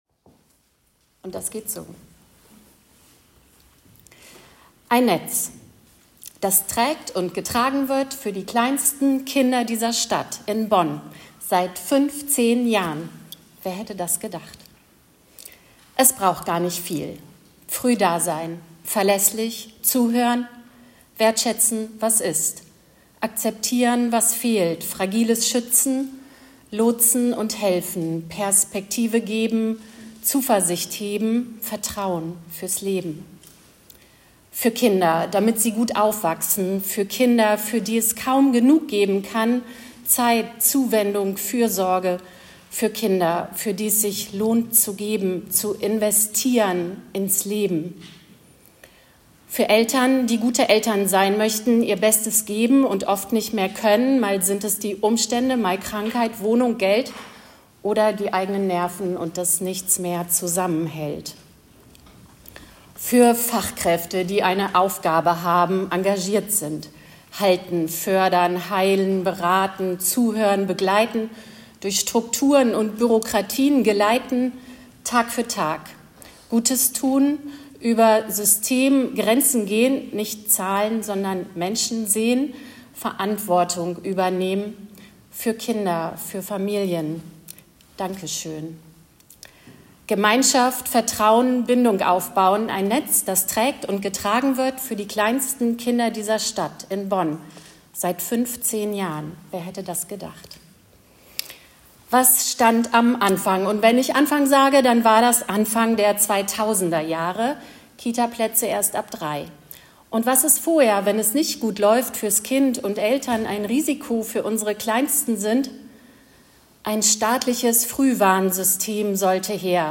Jahren Frühe Hilfen Bonn findet Ihr hier: Poetry Slam: Ein Netz, das trägt und getragen wird.